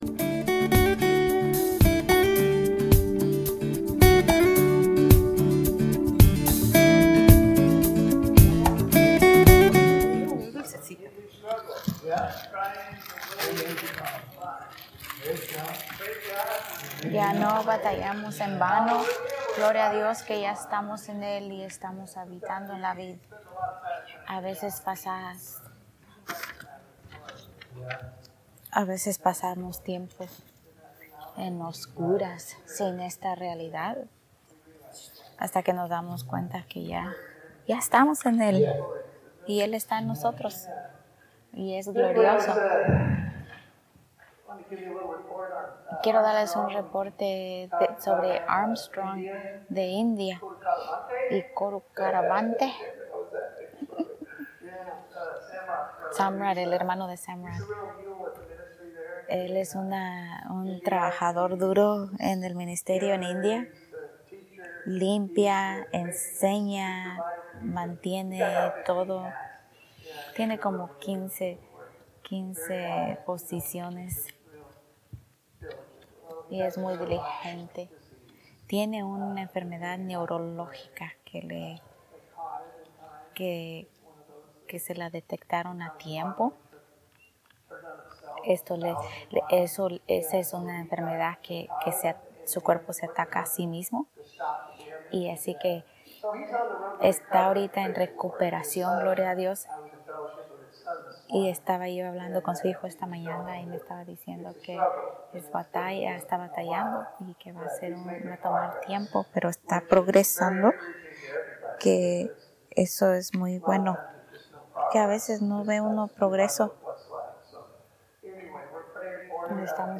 Sermones en Español